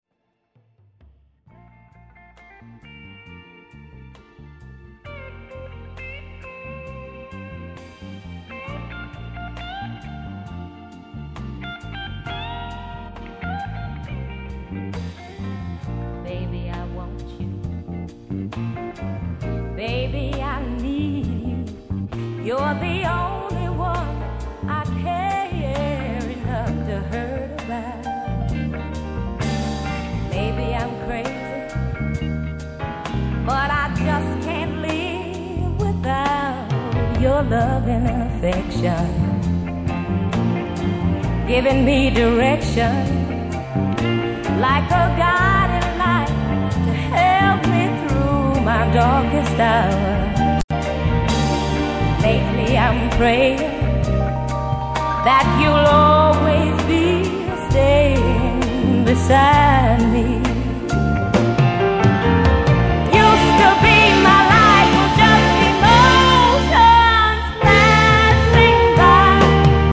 Ａ面、Ｂ面それぞれ曲が自然な流れでつながった意欲的な作りのフリー・ソウル・ファンからの支持も高い作品。
繊細で細やかな印象の本作品が支持されるのもうなづける。